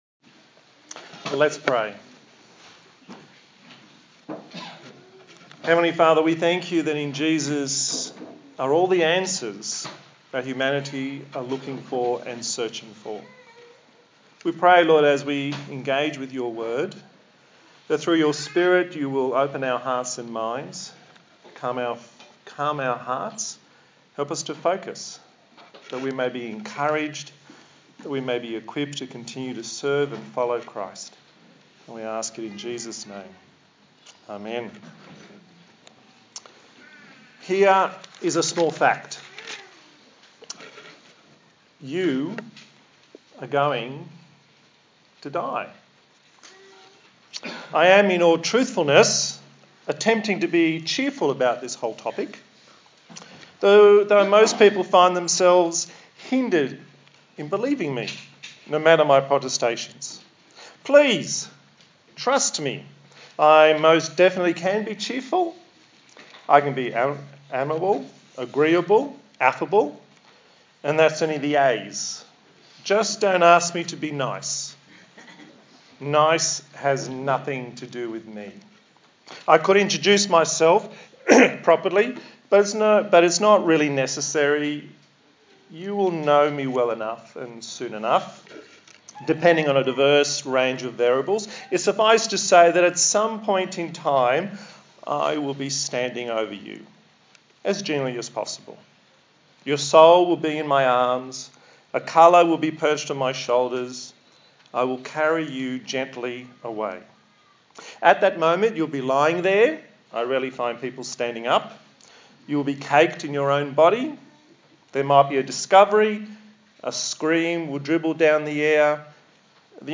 A sermon in the series on the book of John
Service Type: Sunday Morning